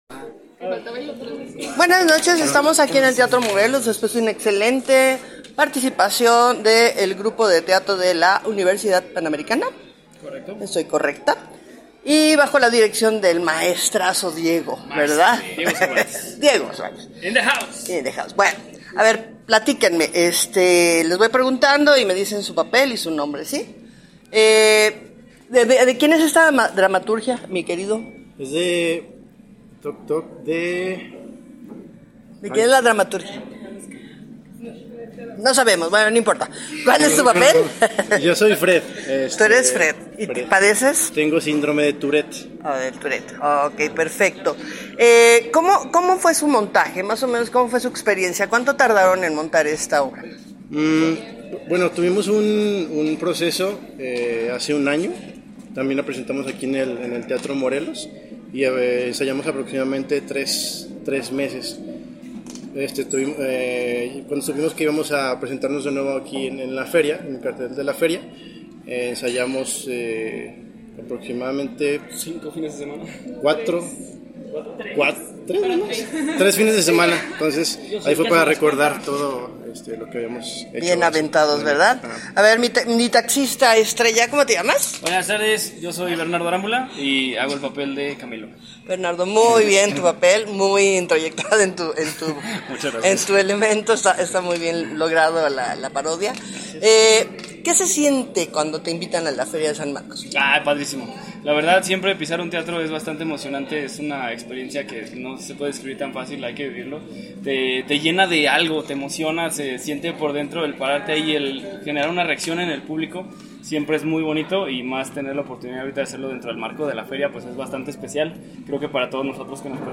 Simpática comedia de la cual puede saber más si escucha la entrevista que se realizó a el grupo.